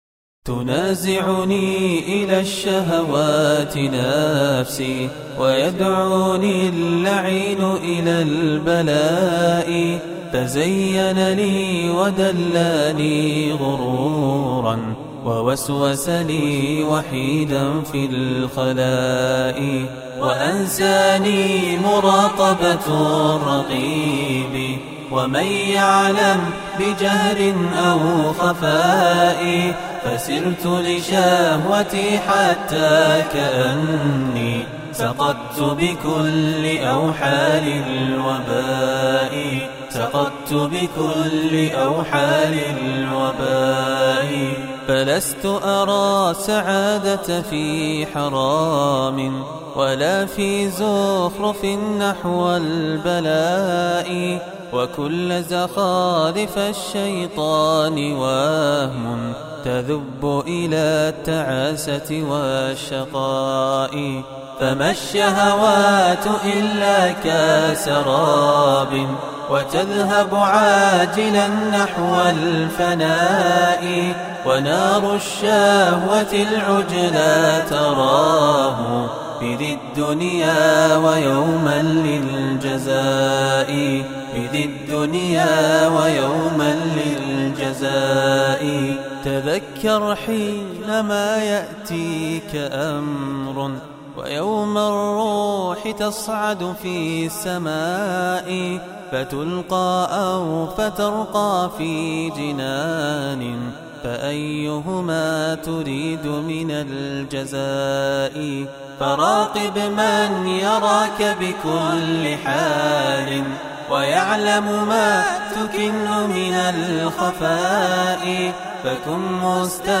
الإنشاد و الهندسة الصوتية